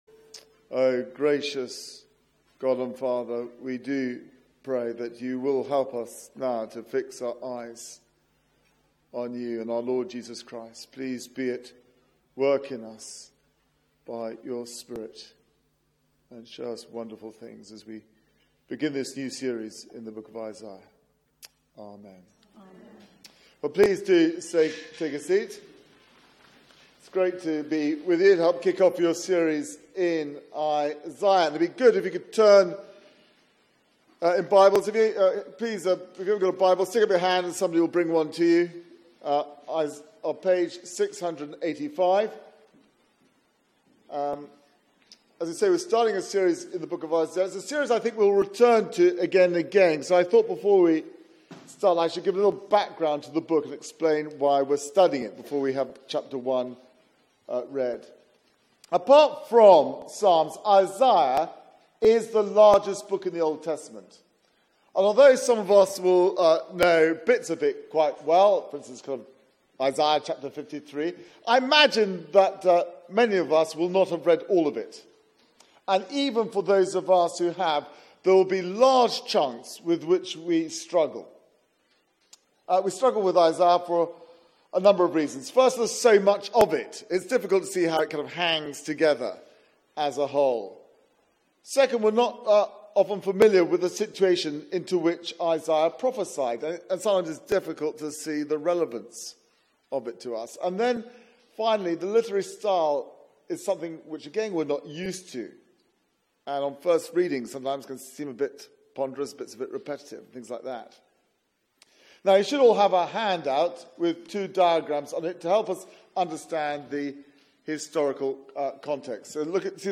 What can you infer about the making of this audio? Media for 4pm Service on Sun 17th Sep 2017 16:00 Speaker